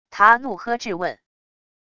他怒喝质问wav音频